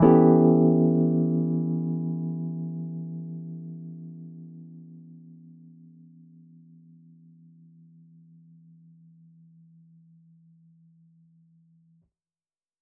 JK_ElPiano3_Chord-Em13.wav